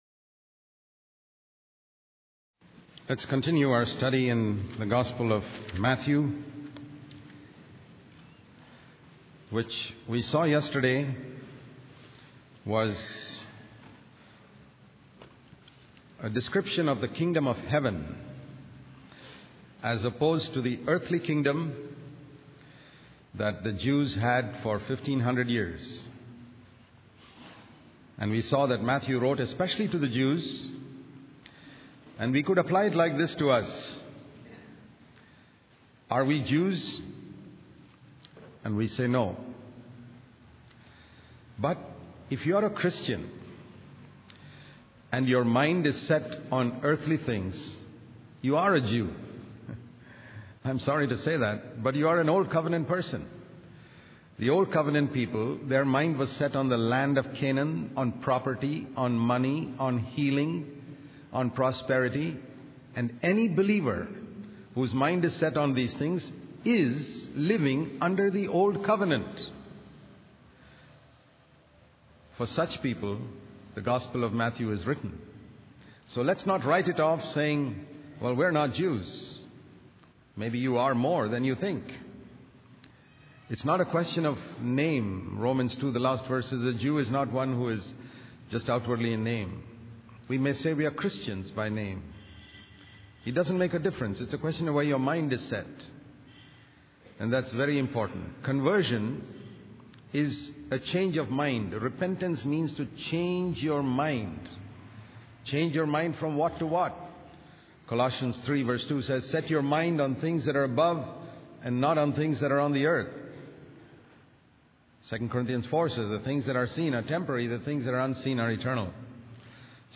In this sermon, the speaker emphasizes the power of memory and the human brain as the original computer. He explains that our memory stores every single thing we have done and said since birth. The speaker also highlights the ease of changing external forms and rituals without true transformation of the heart.